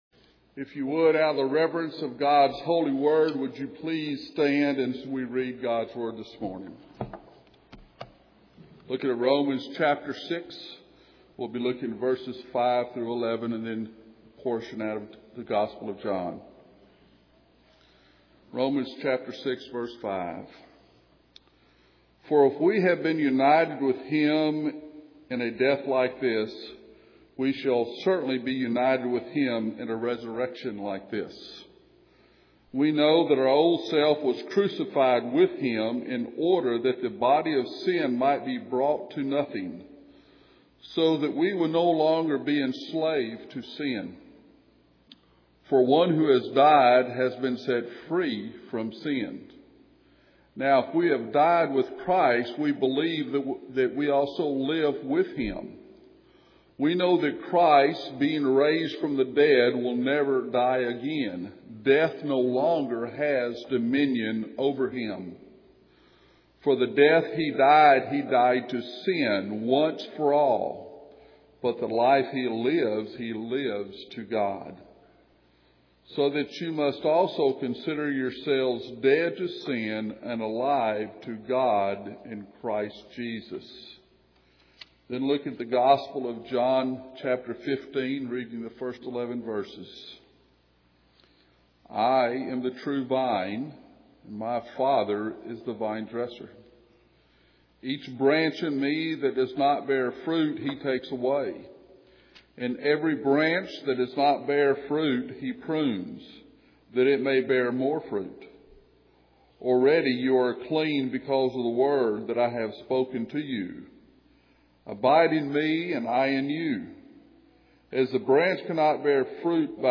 John 15:1-11 Service Type: Sunday Morning « The Golden Chain